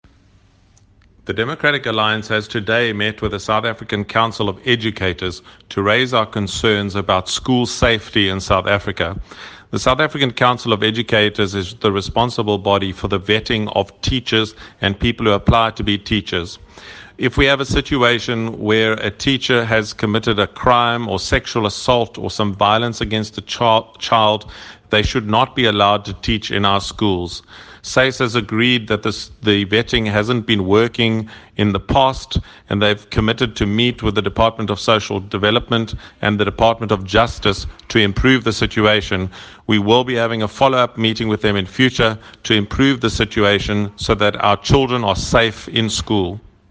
Please find attached a soundbite in
English by Shadow Minister of Basic Education, Ian Ollis MP.